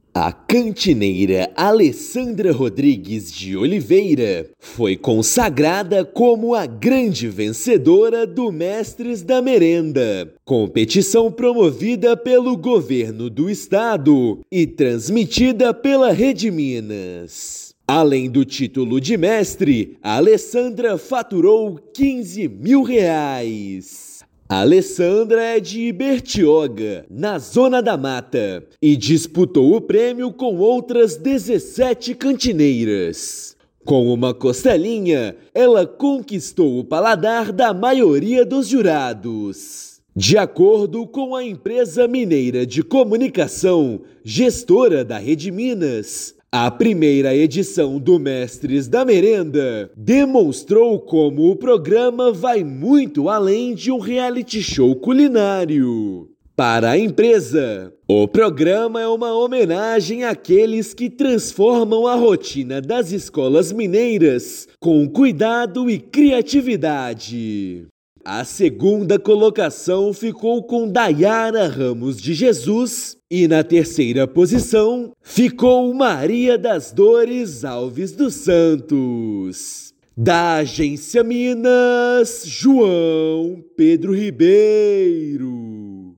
[RÁDIO]